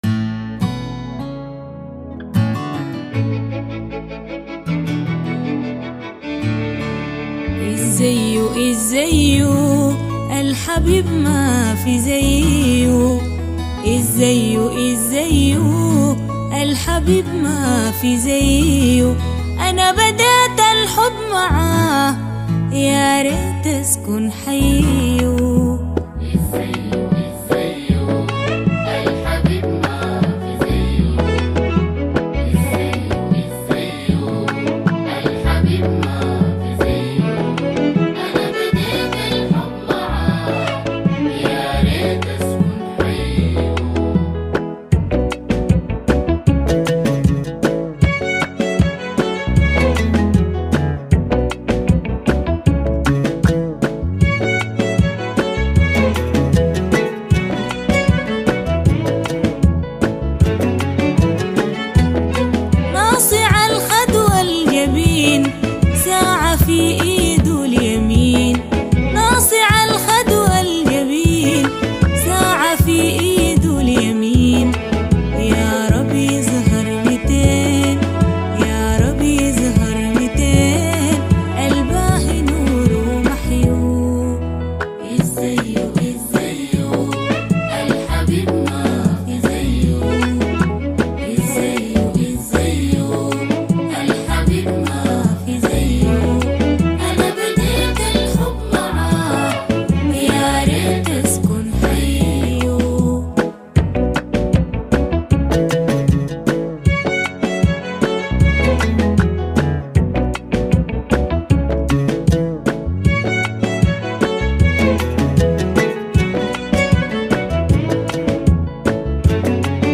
اغاني سودانية